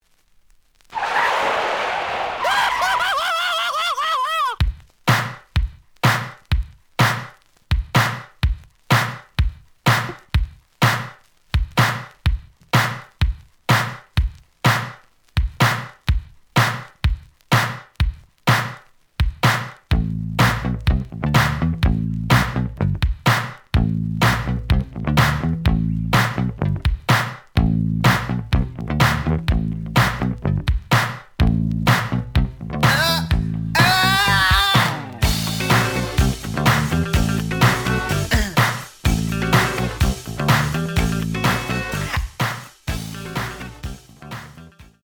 試聴は実際のレコードから録音しています。
●Genre: Funk, 80's / 90's Funk
●Record Grading: EX- (盤に若干の歪み。多少の傷はあるが、おおむね良好。)